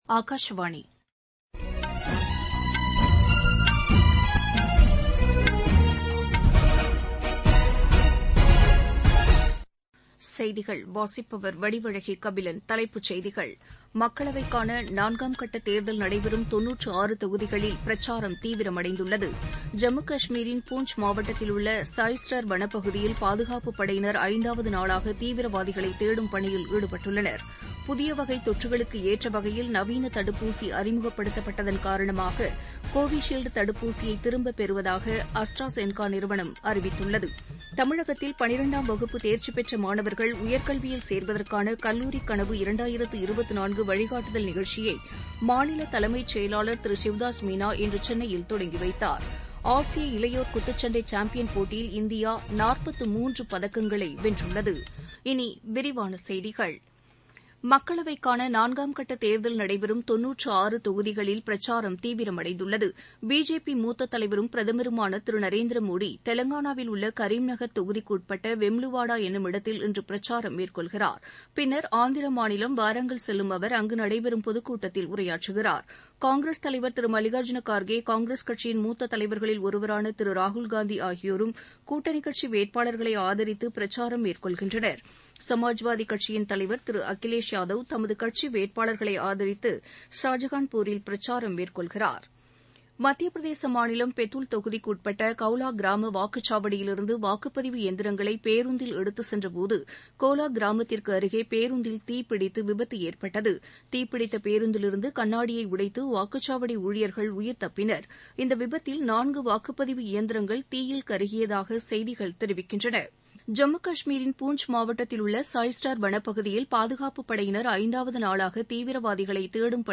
Transcript summary Play Audio Evening News